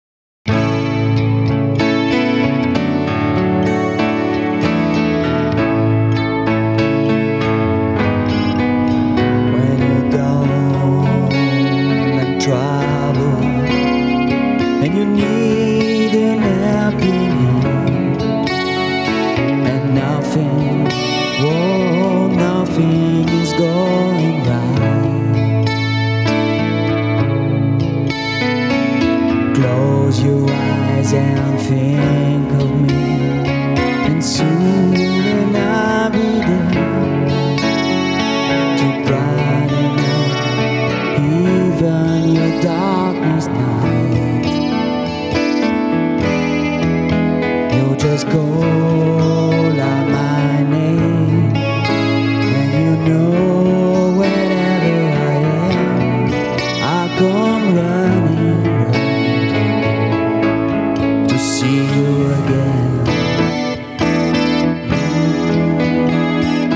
Le 05 et 06/08/2006 au studio "Mafaldine", Lamalou, France
Guitare
Claviers, Chant, Choeurs